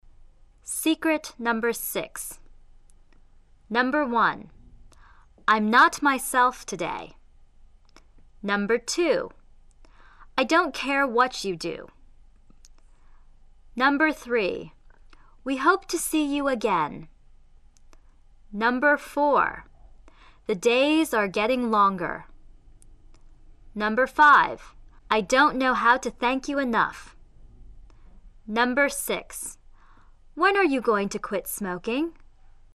英语是否好听，主要取决于元音是否饱满、到位！